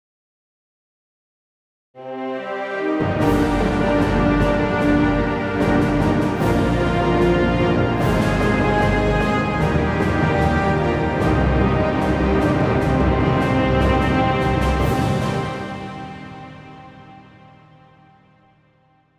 מוזיקה קולנועית